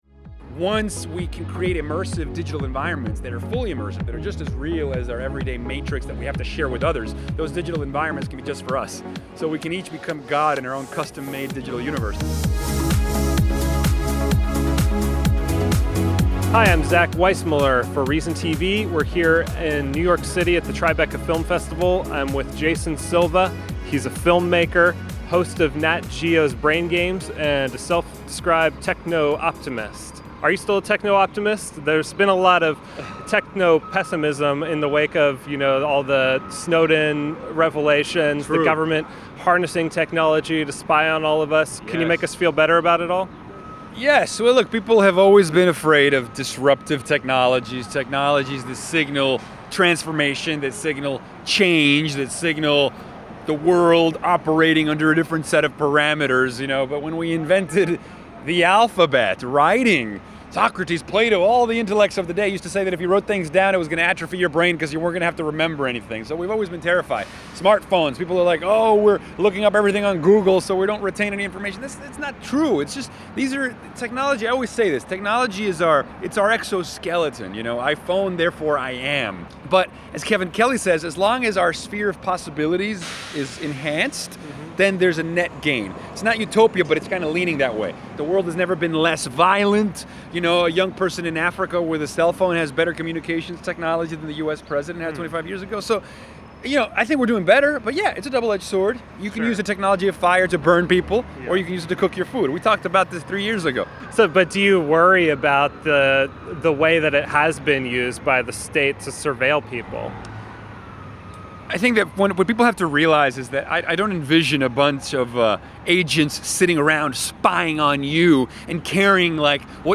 Silva was at the 2014 Tribeca Film Festival promoting his web series Shots of Awe. Silva says the popularity of wearable devices will change the future of how we consume media.